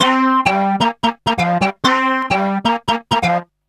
___BOTTLES 2.wav